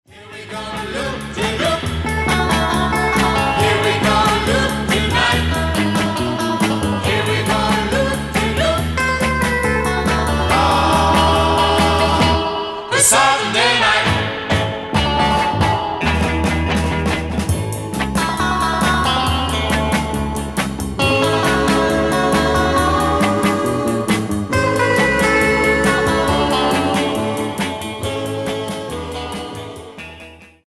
Genre : Rock’ n’ Roll, Instrumental